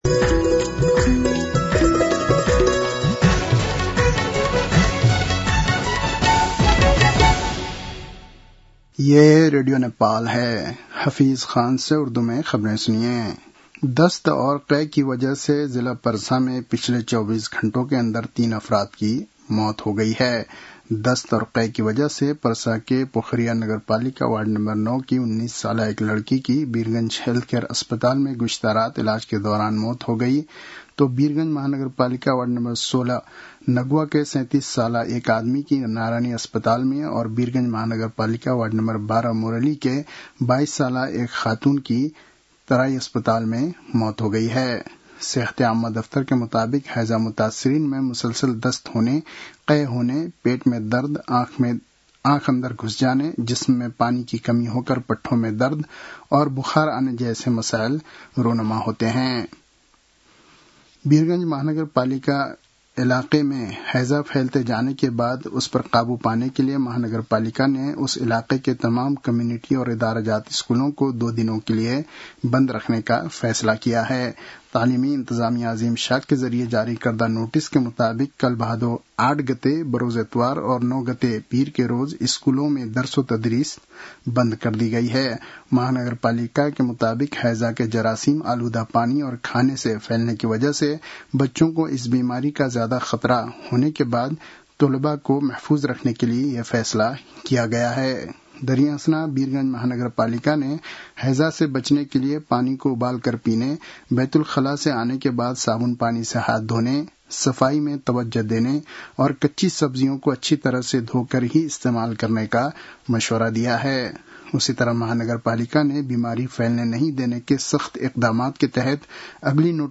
उर्दु भाषामा समाचार : ७ भदौ , २०८२